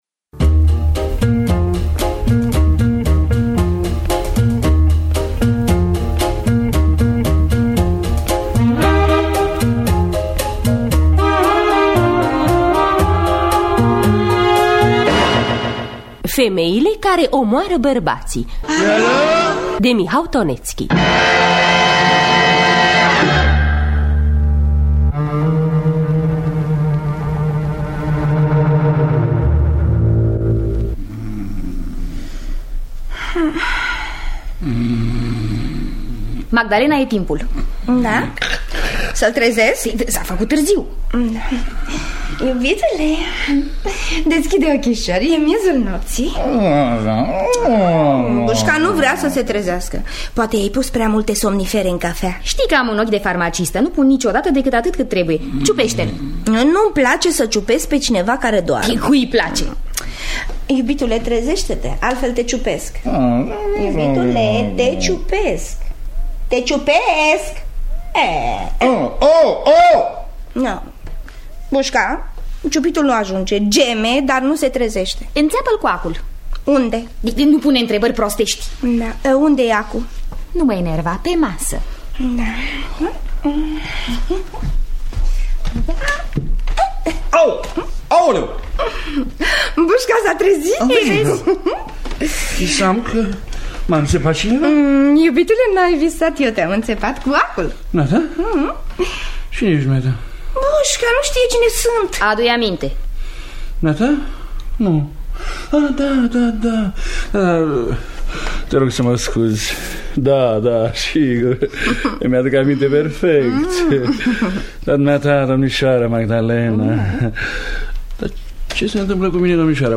În distribuţie: Sanda Toma, Coca Andronescu, Toma Caragiu.